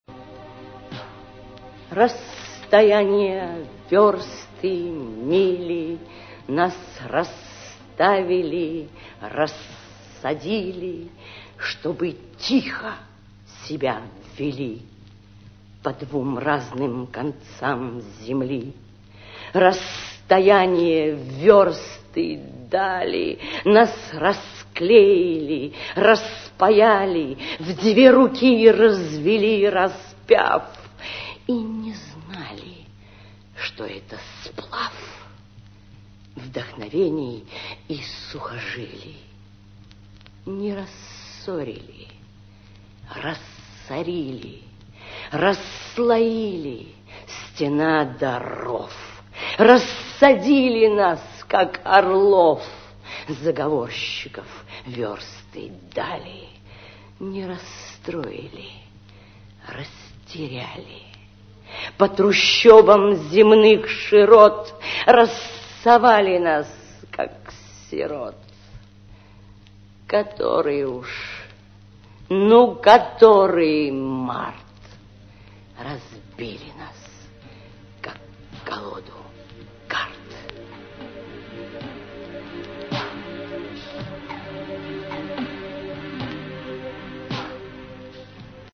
М. Цветаева (Читает А. Фрейндлих) - Расстояния, вёрсты, мили